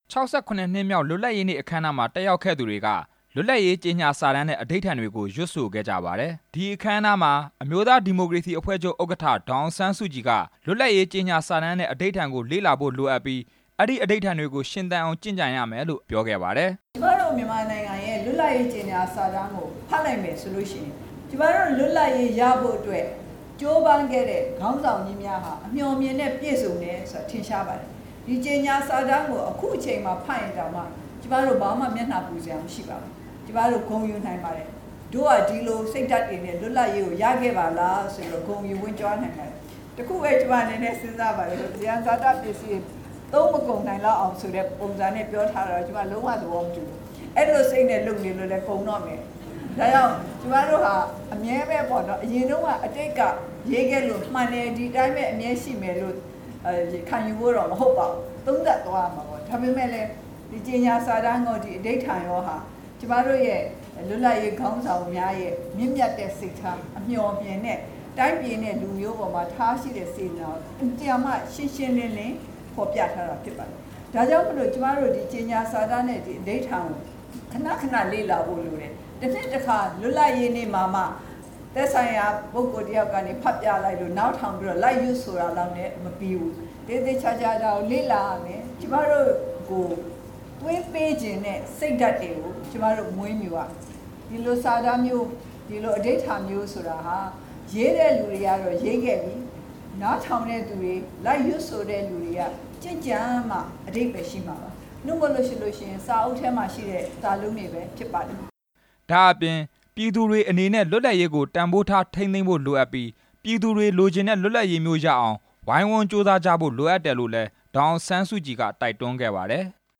ဒီကနေ့ ကျရောက်တဲ့ ၆၇ နှစ်မြောက် လွတ်လပ်ရေးနေ့အခမ်းအနားကို ရန်ကုန်မြို့ ရွှေဂုံတိုင်လမ်းပေါ်မှာရှိတဲ့ တော်ဝင်နှင်းဆီခန်းမမှာ အမျိုးသားဒီမိုကရေစီ အဖွဲ့ချုပ်က ကျင်းပခဲ့ပါတယ်။
အခမ်းအနားကို အမျိုးသားဒီမိုကရေစီအဖွဲ့ချုပ် ဥက္ကဌ ဒေါ်အောင်ဆန်းစုကြည်နဲ့ နာယက ဦးတင်ဦးတို့ တက်ရောက်ပြီး မိန့်ခွန်းပြောကြားခဲ့ပါတယ်။